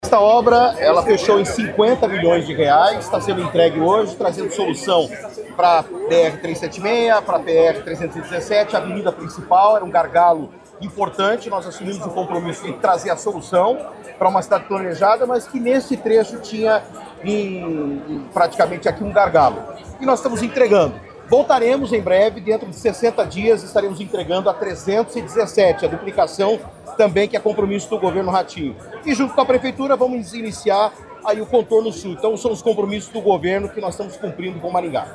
Sonora do secretário de Infraestrutura e Logística, Sandro Alex, sobre a inauguração do novo Trevo do Catuaí, em Maringá